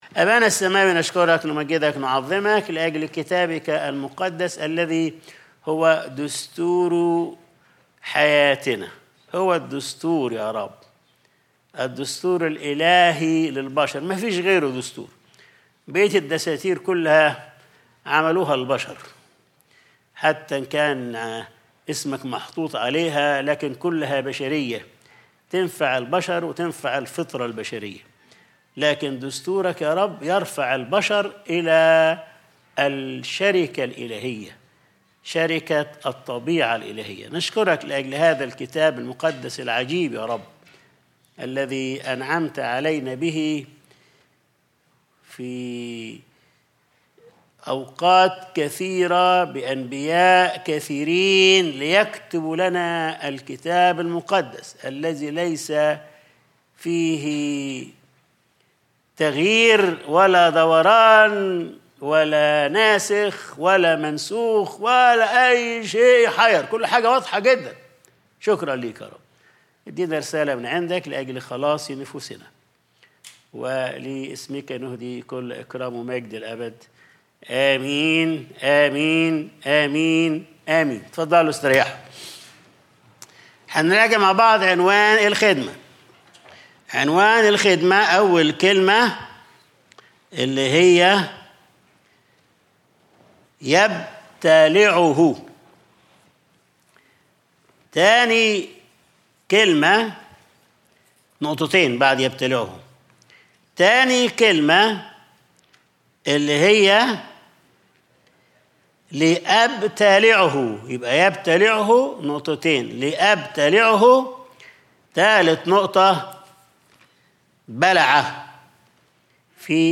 Sunday Service | يبتلعه .. لأبتلعه .. يبلع